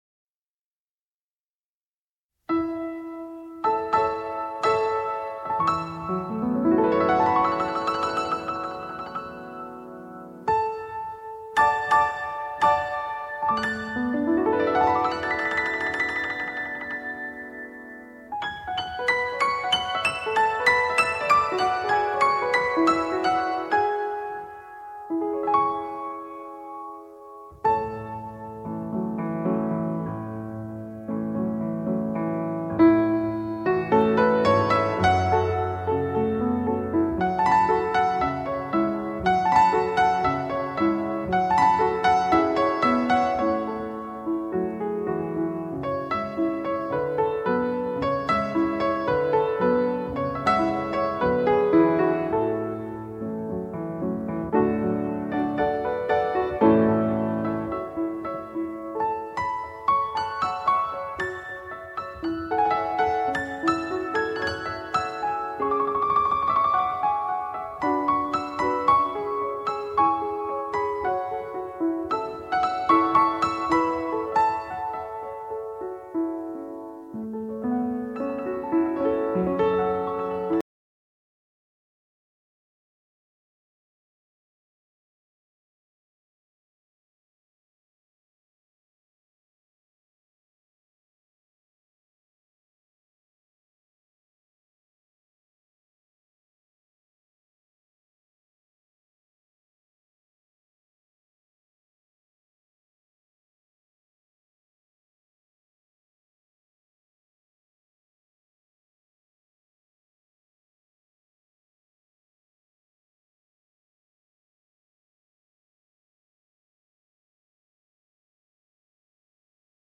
全新数码音频处理
这首钢琴曲是根据家喻户晓的同名民族器乐曲改编的，但比原曲更优美，更富于诗情画意。